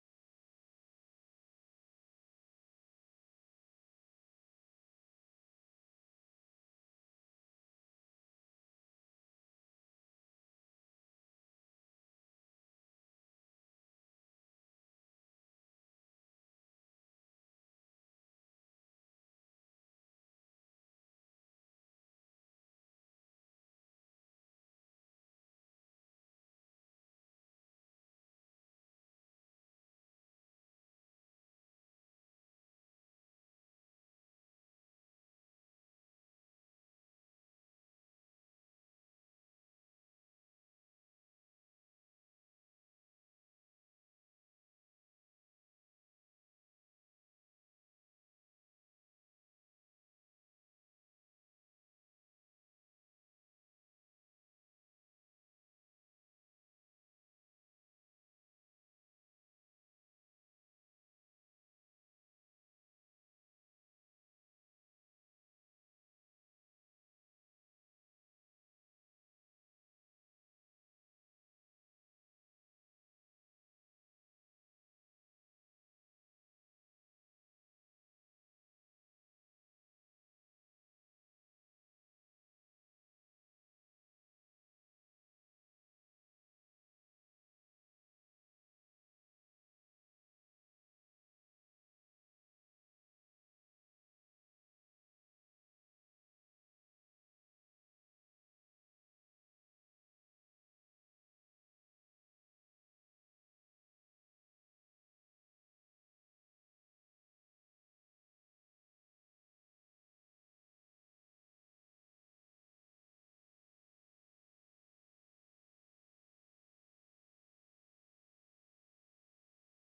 sound file for 13 minute notification